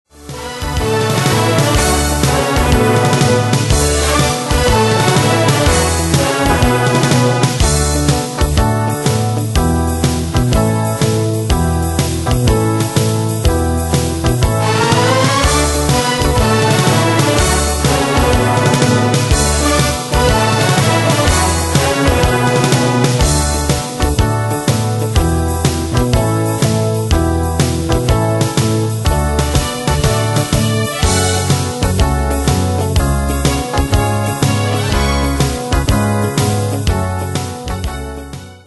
Style: Retro Année/Year: 1964 Tempo: 123 Durée/Time: 2.57
Danse/Dance: TripleSwing Cat Id.
Pro Backing Tracks